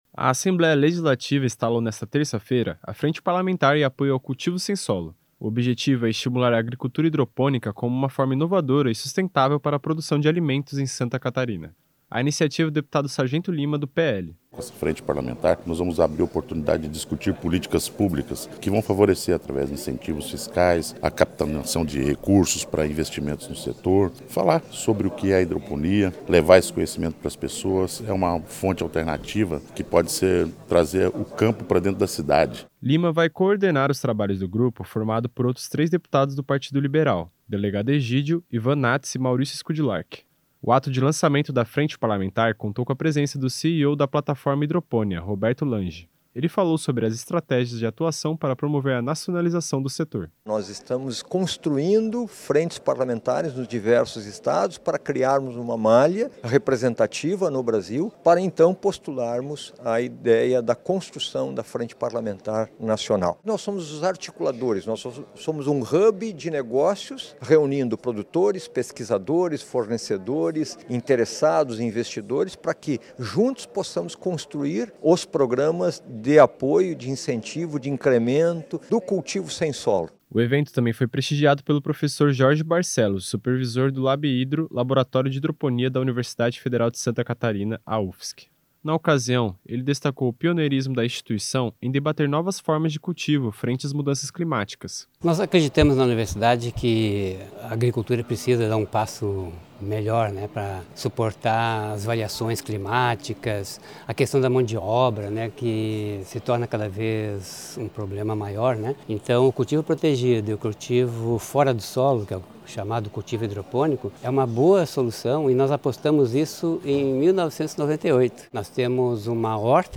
Entrevistas com:
- Sargento Lima (PL), coordenador da Frente;